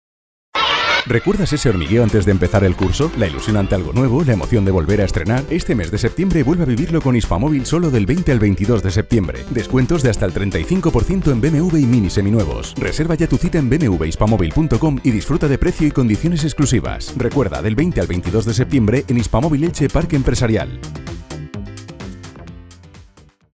locutores, voice over locutor cuña de radio